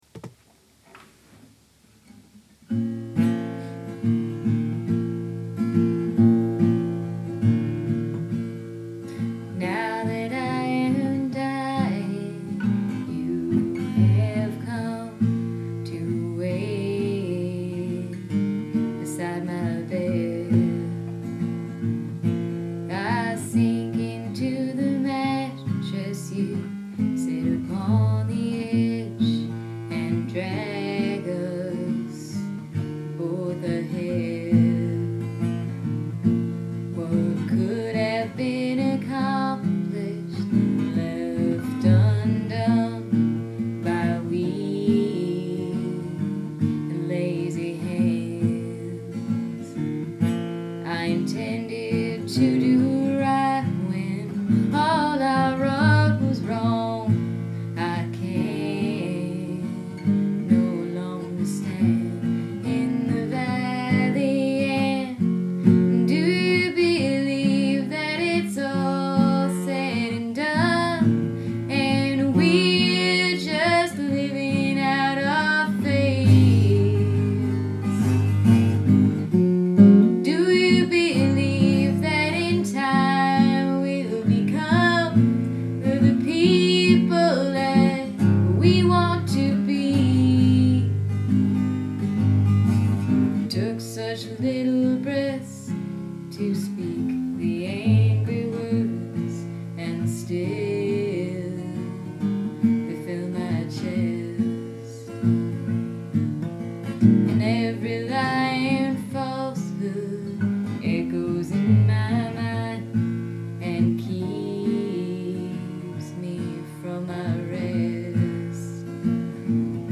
Do You Believe (demo) - MP3